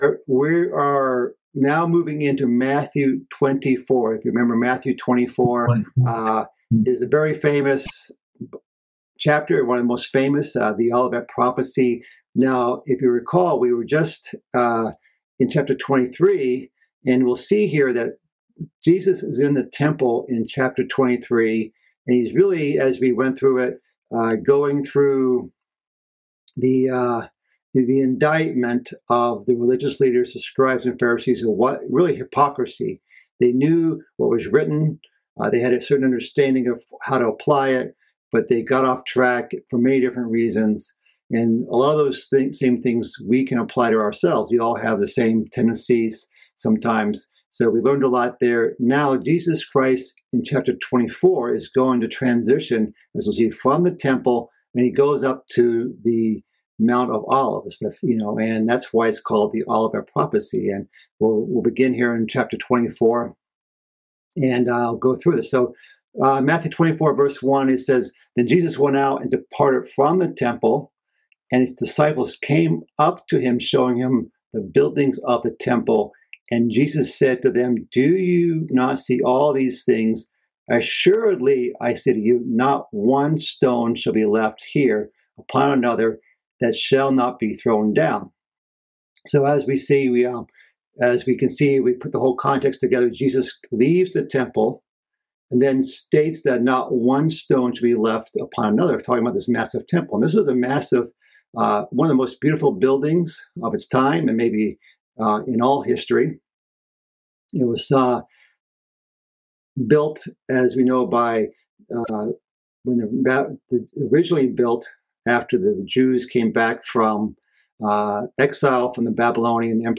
Bible Study - Fifth Discourse Part 6 - Matthew 24:1-4